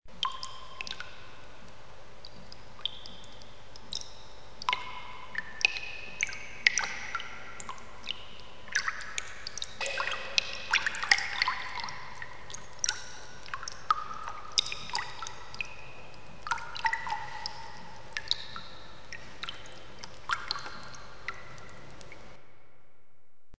drips.mp3